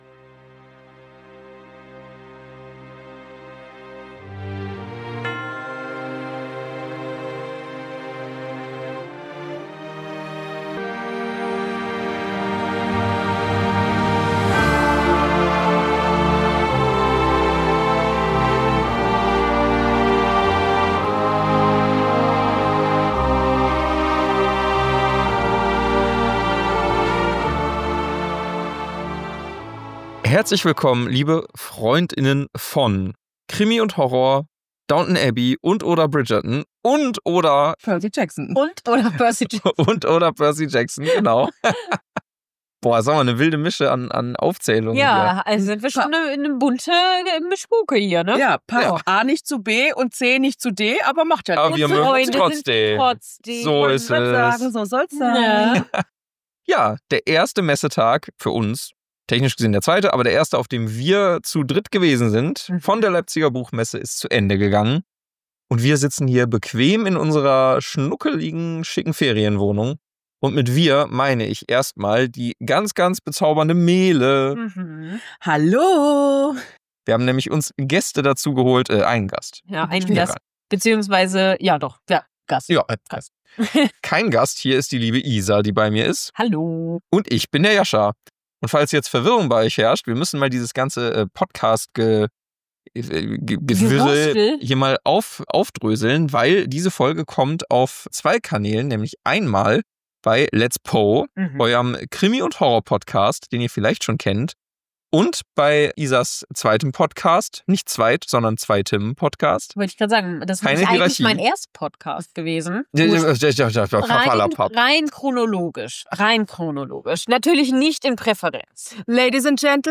Euch erwarten eine Menge Quatsch, viel Liebe und einige großartige Interviews!